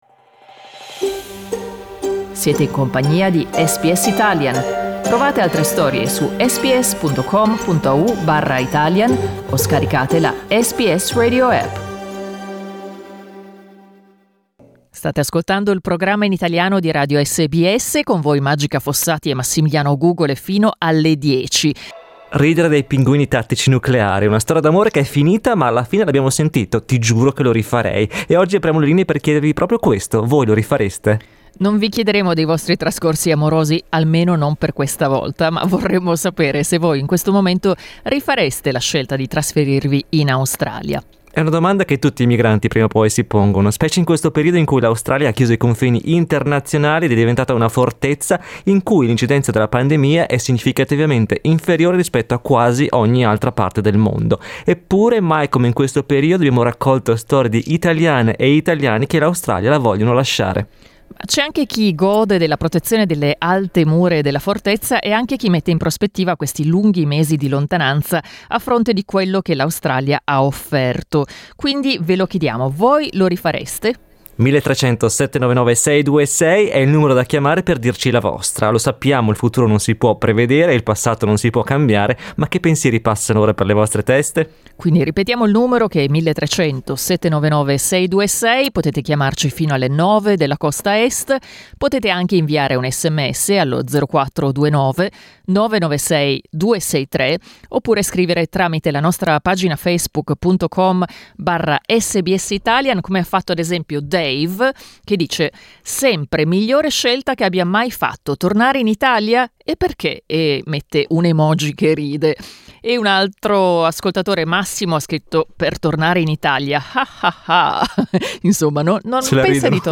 Abbiamo aperto le linee durante il programma per chiedervi se rifareste la scelta di trasferirvi in Australia, pur sapendo di non poterne uscire magari per anni. Ascolta gli interventi dei nostri ascoltatori e ascoltatrici: LISTEN TO Chi ripeterebbe la scelta di trasferirsi in Australia?